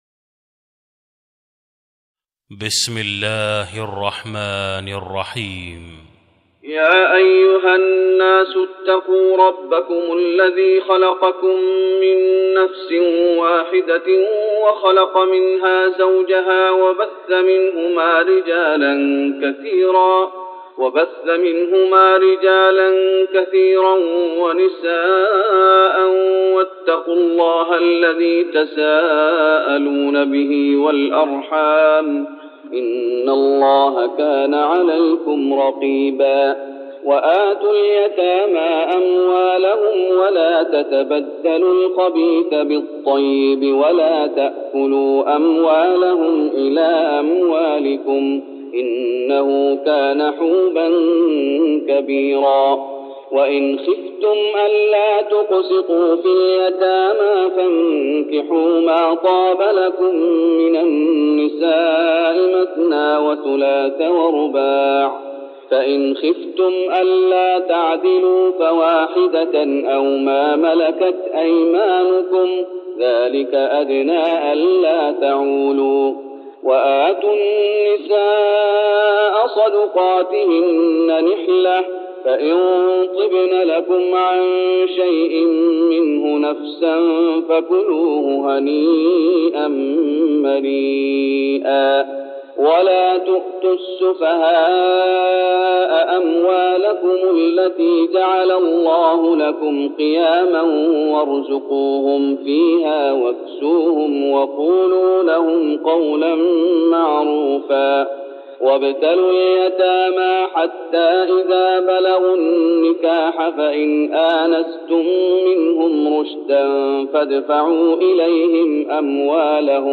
تهجد رمضان 1410هـ من سورة النساء (1-22) Tahajjud Ramadan 1410H from Surah An-Nisaa > تراويح الشيخ محمد أيوب بالنبوي عام 1410 🕌 > التراويح - تلاوات الحرمين